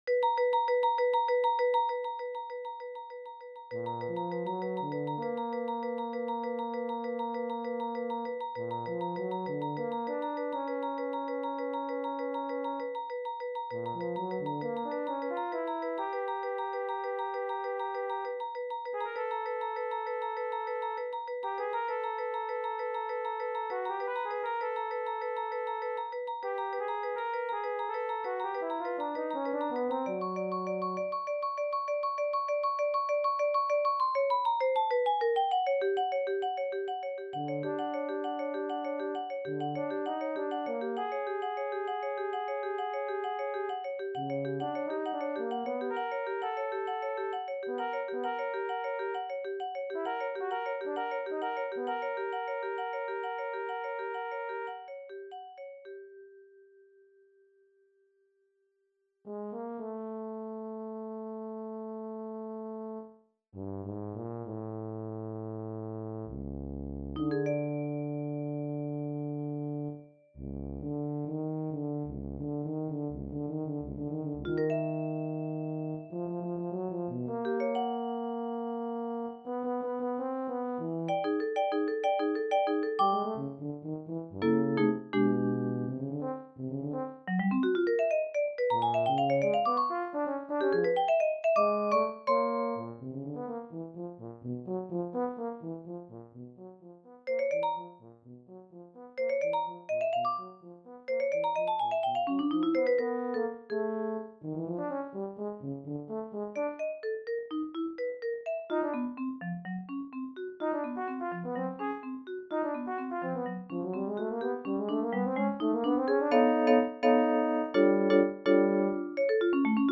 avec vibraphone
Euphonium (Solo), Euphonium C – Clé de fa (Solo)
Composition Originale
Accompagnement au Vibraphone